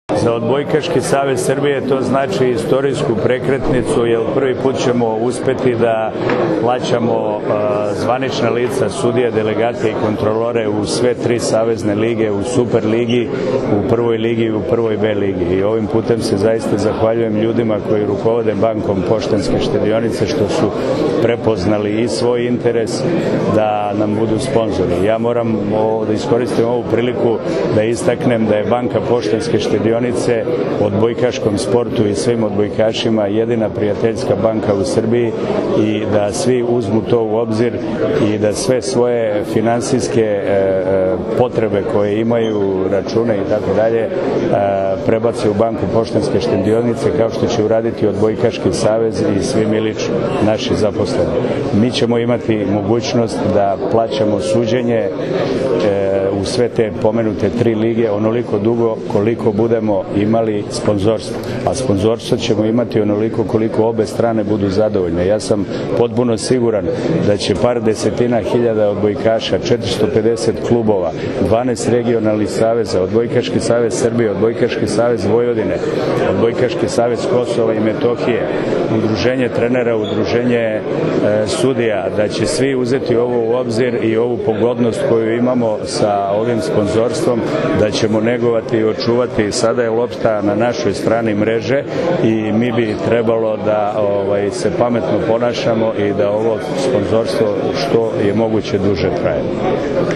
Odbojkaški savez Srbije i Banka Poštanska štedionica potpisali su danas Ugovor o saradnji u konferencijskoj sali Turističke organizacije Srbije u Beogradu.
IZJAVA